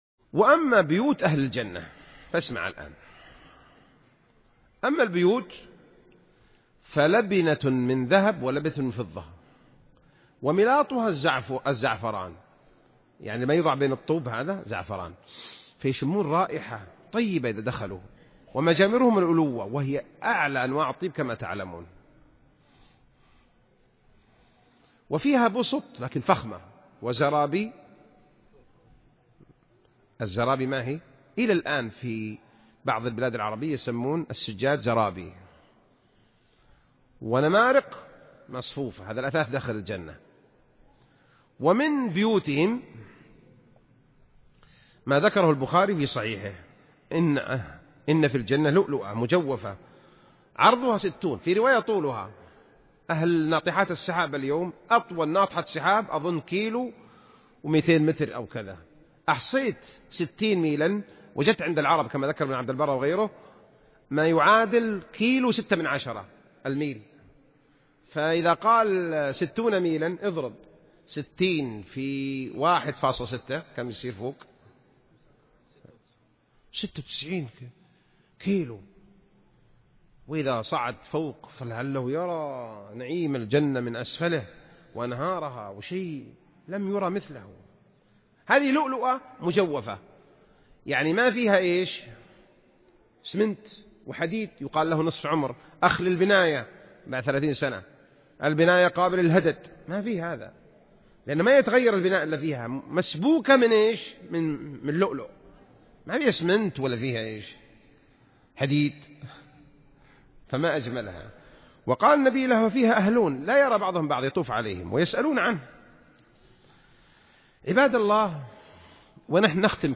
227 [ درر قحطانية ] - بيوت أهل الجنة ولباسهم وطعامهم { محاضرة }